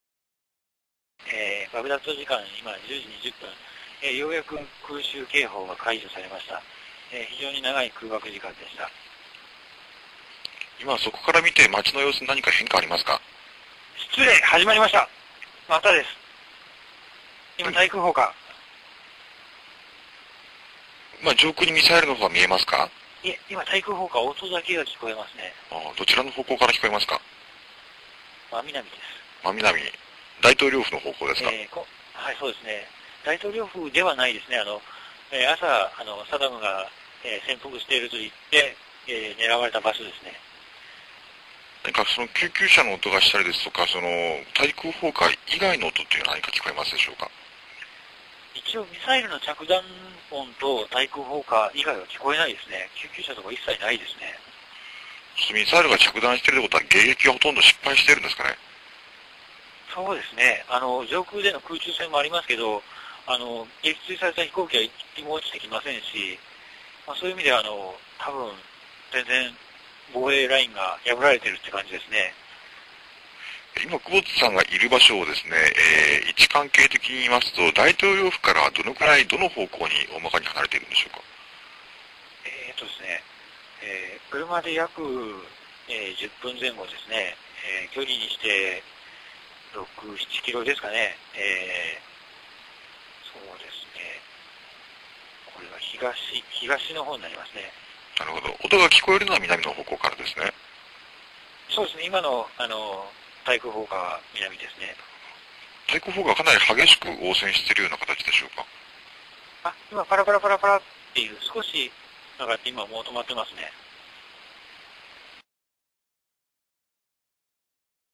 音声リポート